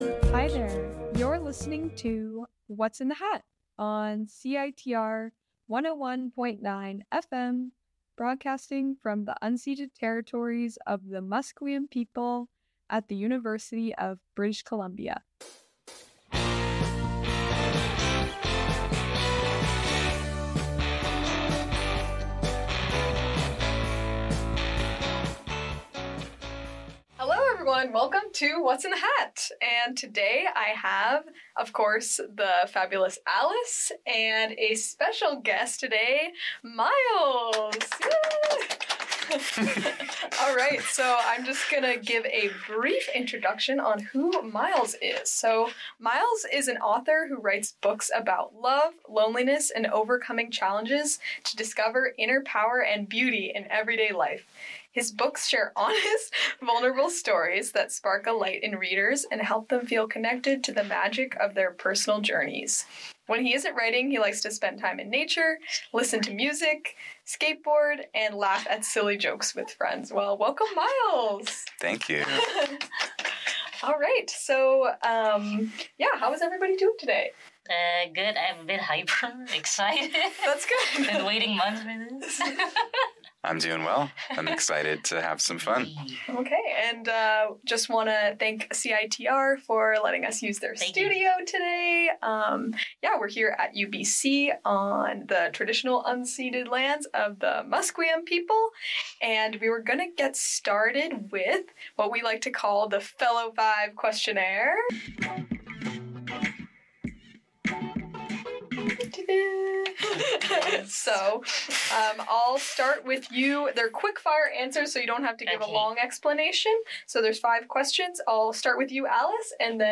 They discuss wellness in a fun, approachable way with personal stories and lots of laughs.